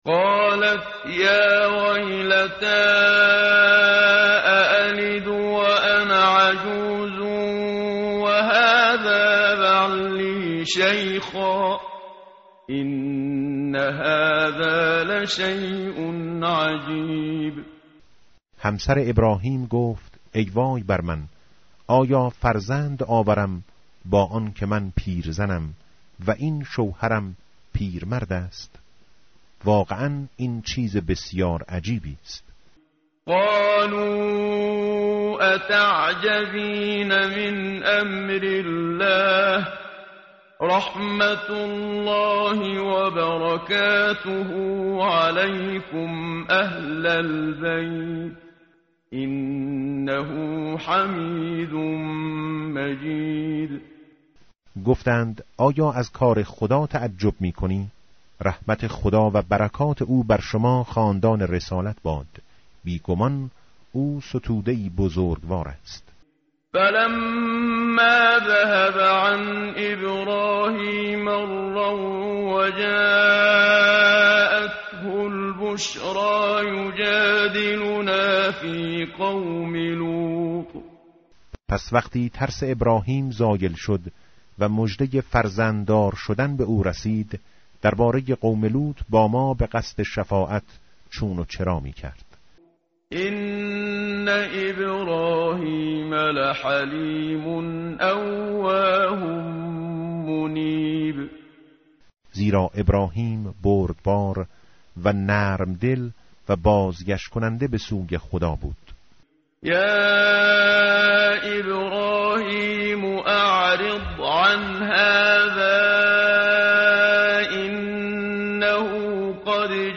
متن قرآن همراه باتلاوت قرآن و ترجمه
tartil_menshavi va tarjome_Page_230.mp3